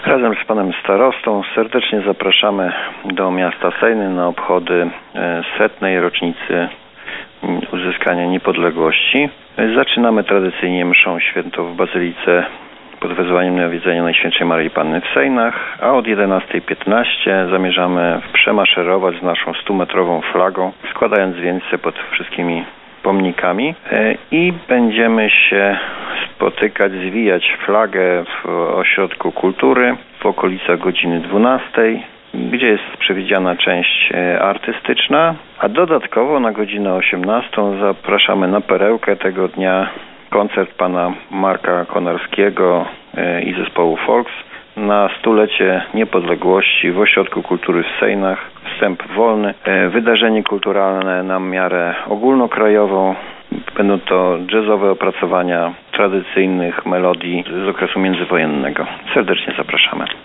Zaprasza Arkadiusz Nowalski, burmistrz Sejn.